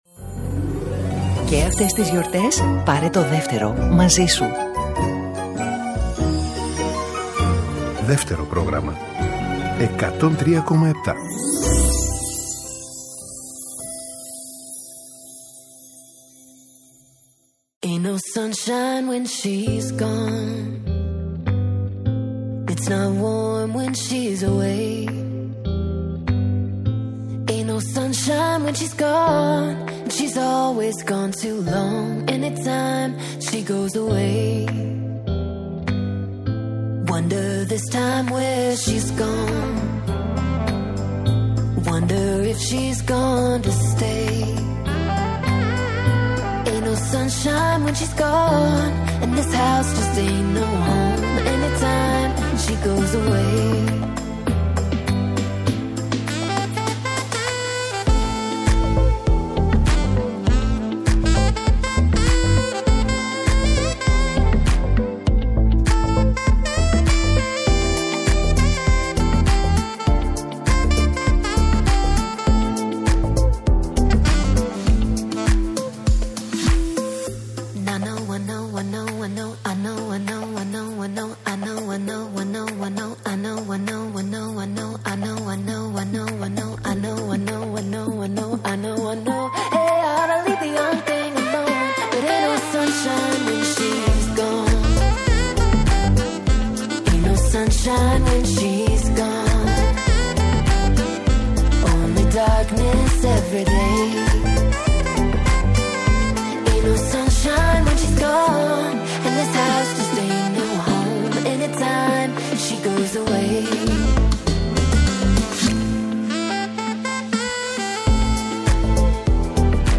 ΣΥΝΕΝΤΕΥΞΕΙΣ